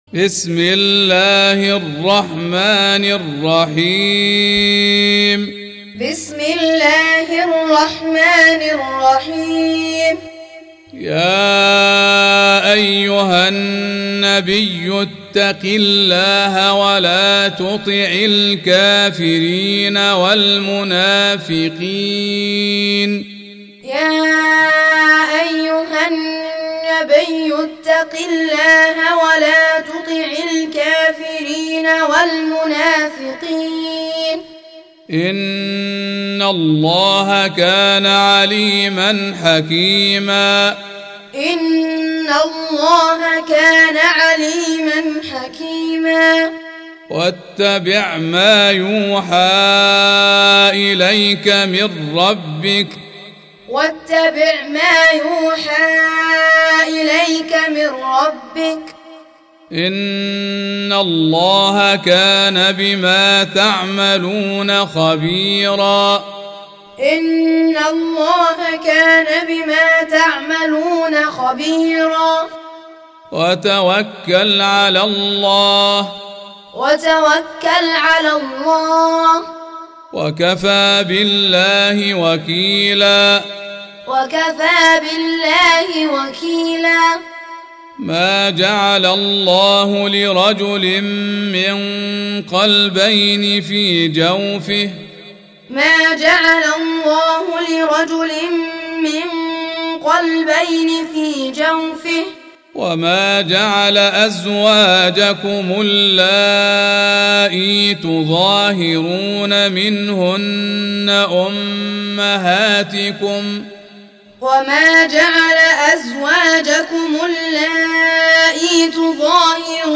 Recitation Teaching Qur'an
Surah Sequence تتابع السورة Download Surah حمّل السورة Reciting Muallamah Tutorial Audio for 33. Surah Al�Ahz�b سورة الأحزاب N.B *Surah Includes Al-Basmalah Reciters Sequents تتابع التلاوات Reciters Repeats تكرار التلاوات